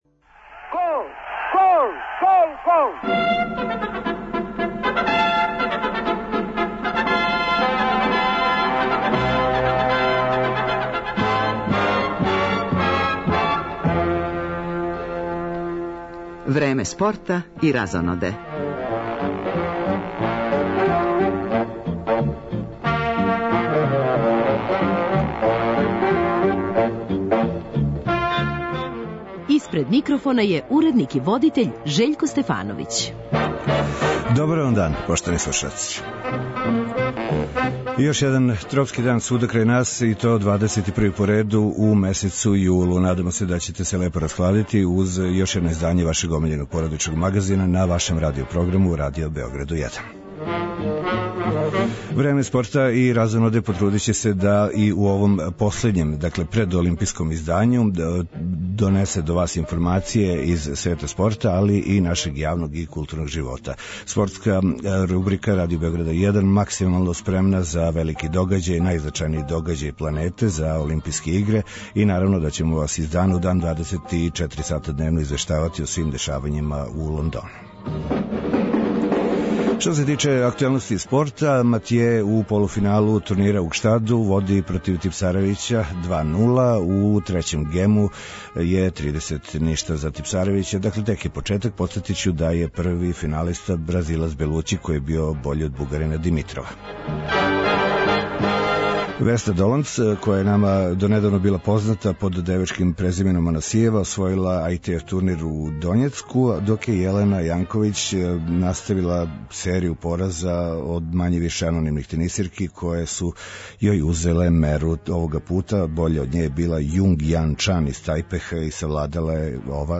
Почело је одбројавање до старта Олимпијских игара у Лондону, па је и данашњи породични магазин Радио Београда 1 идеалан полигон за статистике, прогнозе, подсећања на највеће успехе наших спортиста на овој манифестацији.